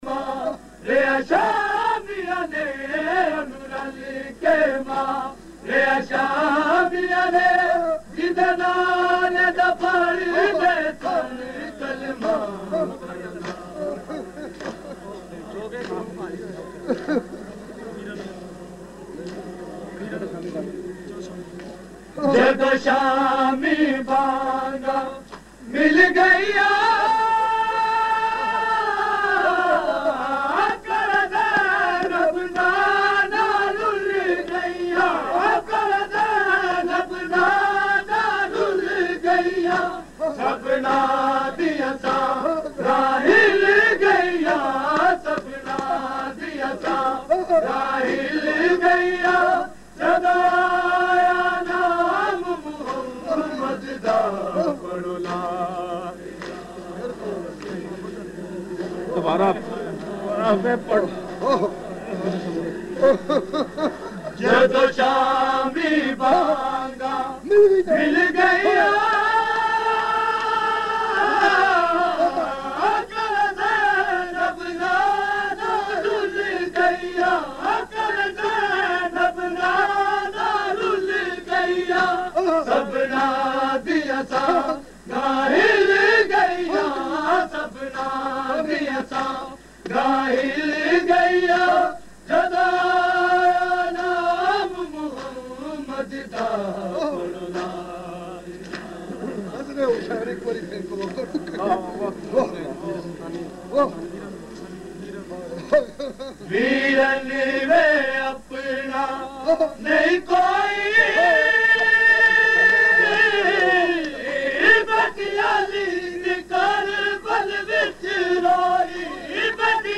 Kalam
Recording Type: Live